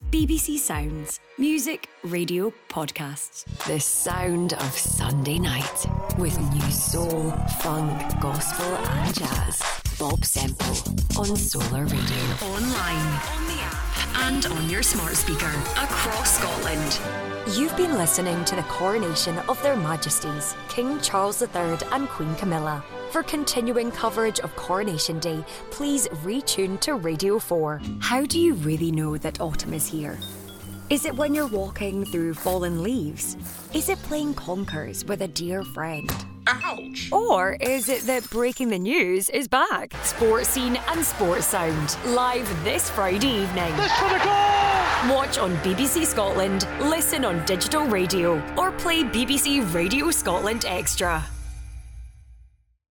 Anglais (écossais)
Imagerie radio
I’m a Scottish full-time Voiceover Artist with a broadcast quality studio.
Fully sound insulated studio with acoustic treatment
Mic: Rode NT2-A
Contralto